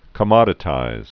(kə-mŏdĭ-tīz)